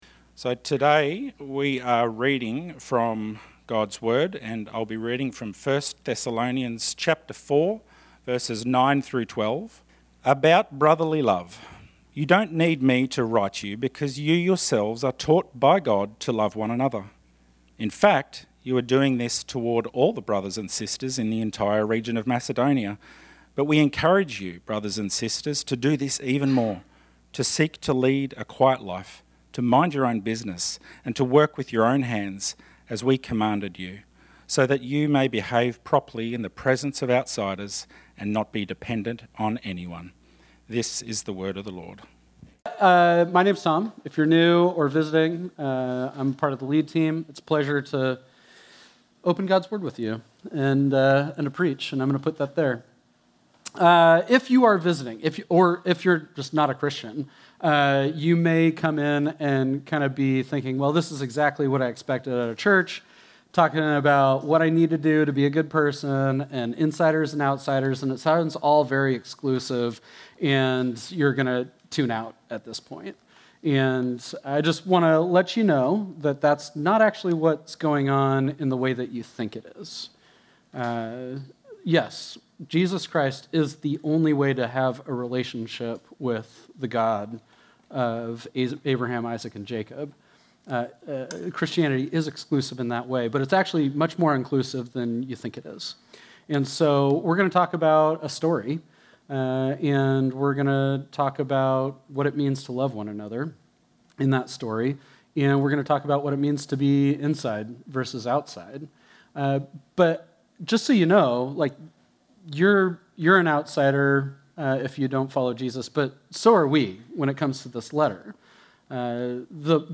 This sermon was originally preached on Sunday, July 27, 2025.